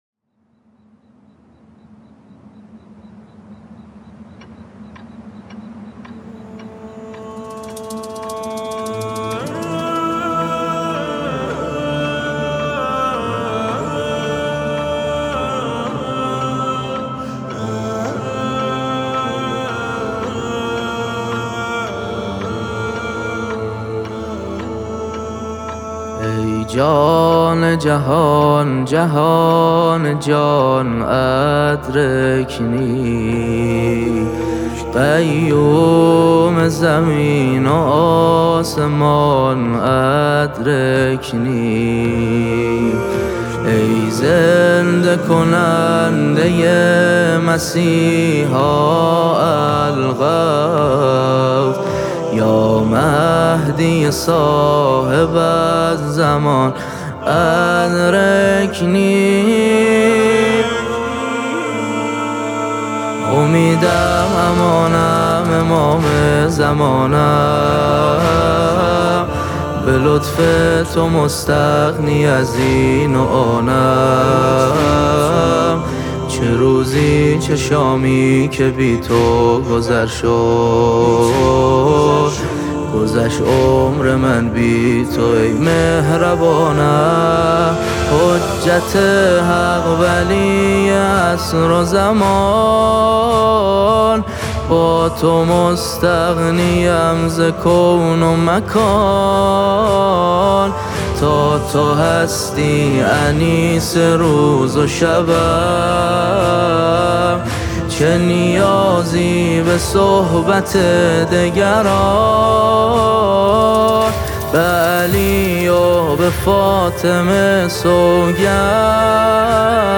نماهنگ مناجات